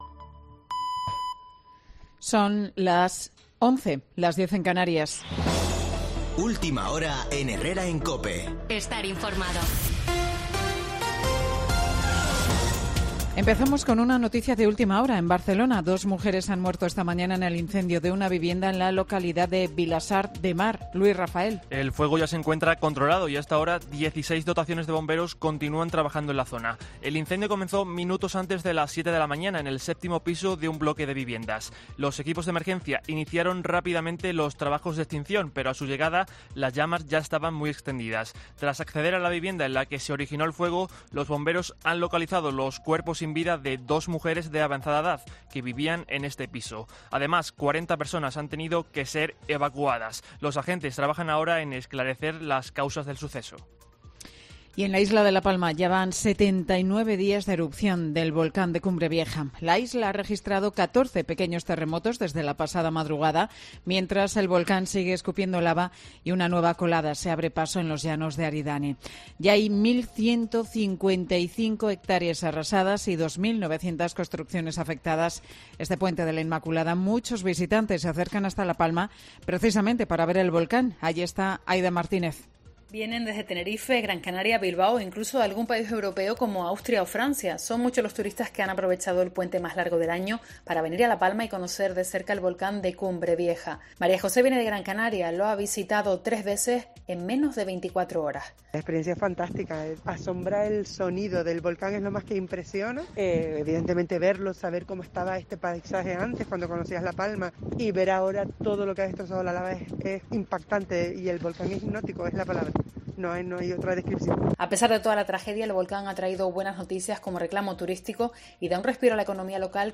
Boletín de noticias COPE del 6 de diciembre de 2021 a las 11.00 horas